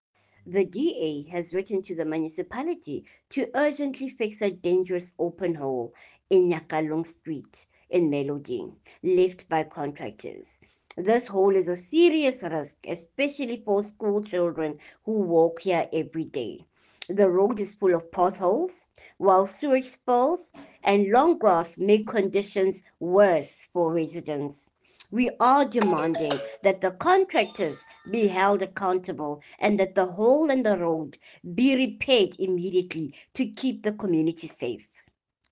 Sesotho soundbites by Cllr Florence Bernado and Afrikaans soundbite by Cllr Jessica Nel.